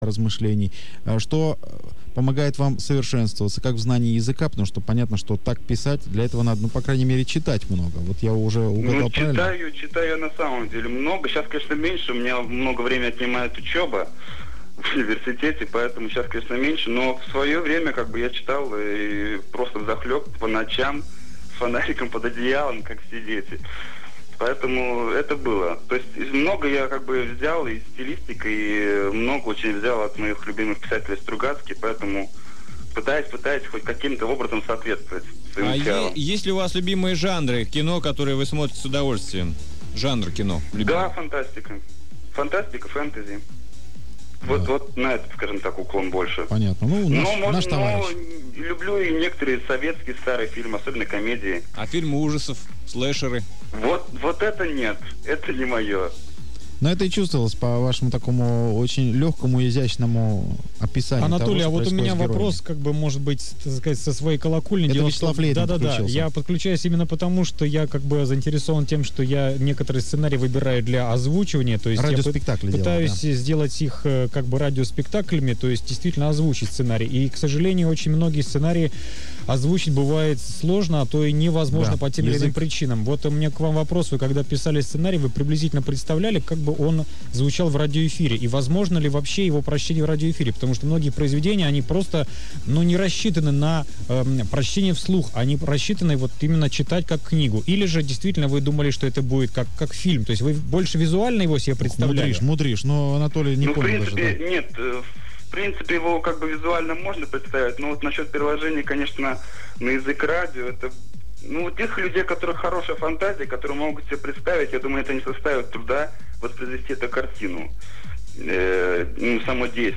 Это интервью я давал в октябре 2005-го года по телефону.
Второе интервью. Вторая часть - 3 мин; 3,46 МB